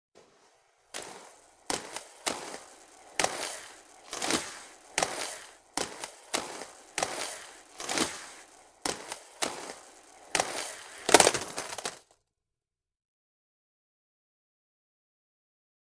Roller Skate; Person Roller Skating Steady, Old Metal Skates.